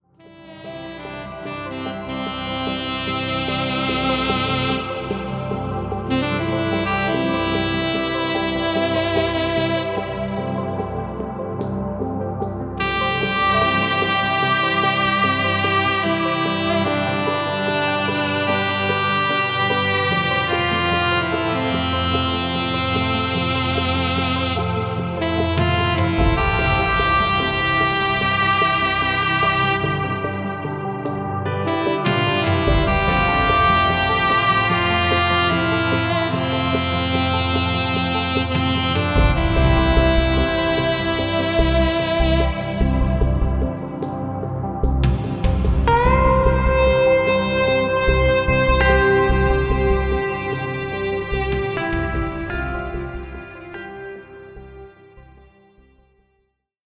Ein entspannter Sommertraum …
Leichte Songs mit Naturgeräuschen laden zu Träumen ein.